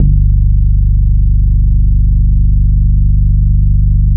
PUMP BASS 1.wav